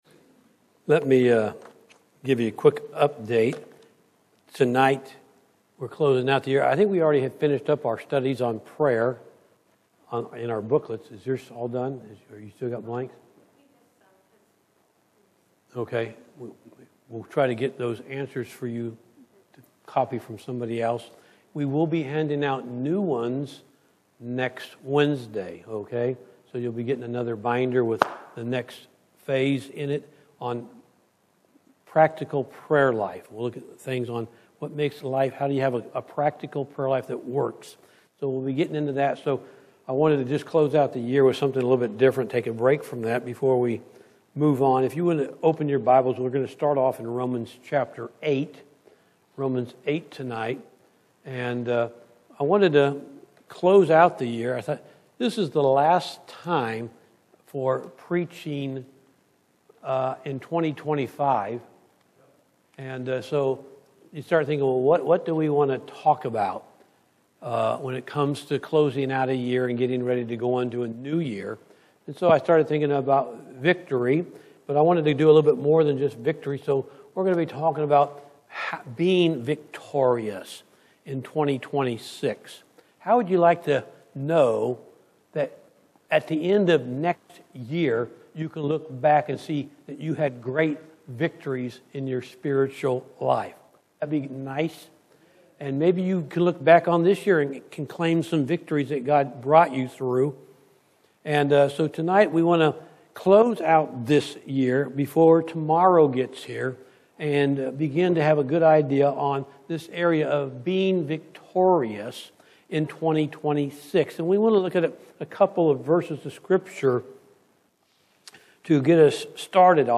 Wednesday PM Service